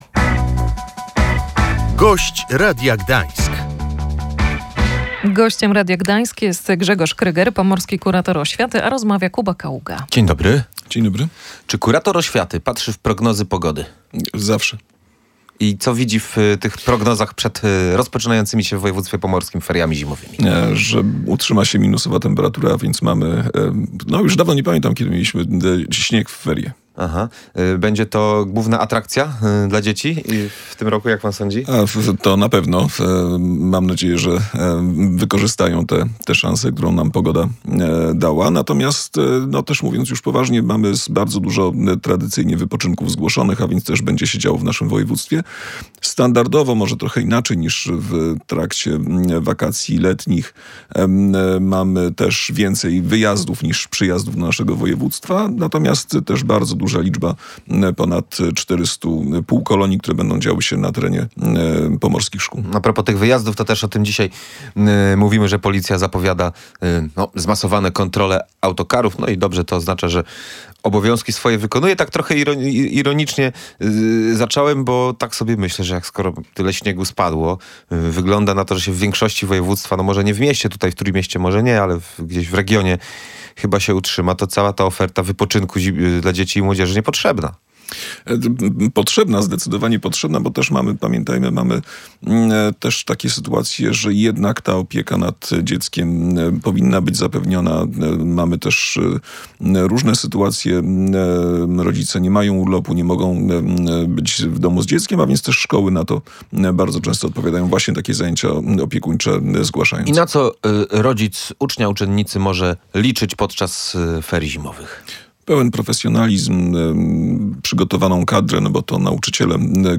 Wykorzystywanie wizerunku dzieci dla celów politycznych jest niedopuszczalne – mówił Gość Radia Gdańsk, Pomorski Kurator Oświaty Grzegorz Kryger. Jego zdaniem wydarzenie w szkole w Kielnie negatywnie wpłynęło na środowisko szkolne.
Grzegorz Kryger zaznaczył na antenie Radia Gdańsk, że protesty polityków nie powinny mieć miejsca przy szkołach.